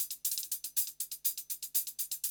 HIHAT LO12.wav